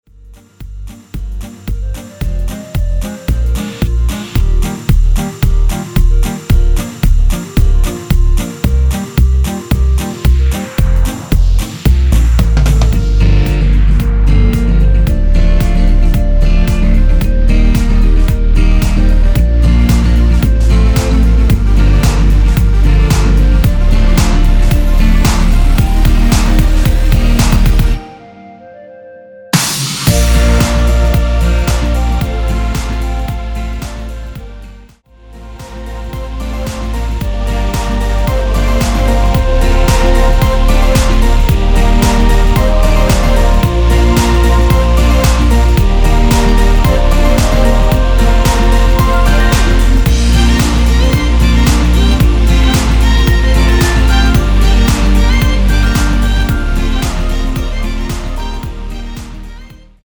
원키에서(-2)내린 멜로디 포함된 MR입니다.
Bb
멜로디 MR이라고 합니다.
앞부분30초, 뒷부분30초씩 편집해서 올려 드리고 있습니다.
중간에 음이 끈어지고 다시 나오는 이유는